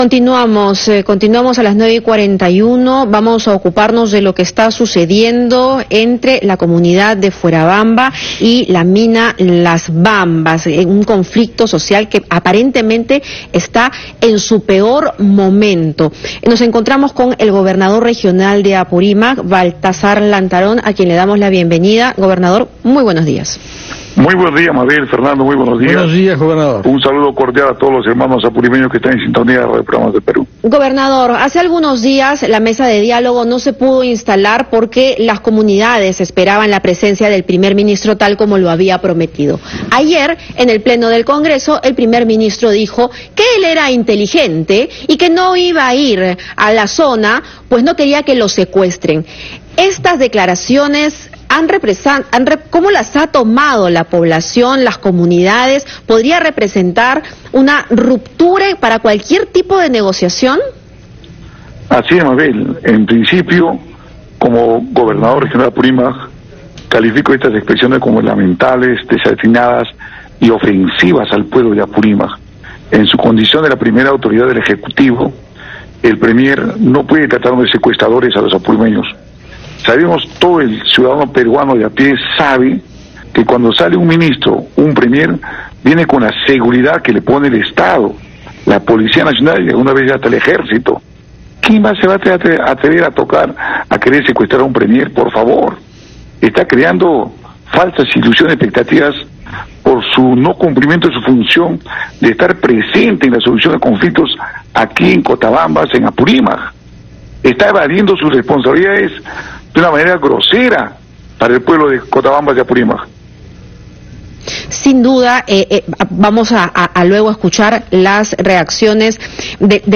Entrevista al gobernador regional de Apurímac, Baltazar Lantarón